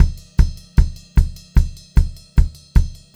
152SPCYMB4-L.wav